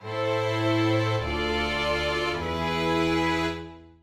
半終止の例:VImIImIII
c1-cadence-half-min.mp3